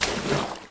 water_dunk.mp3